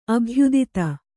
♪ abhyudita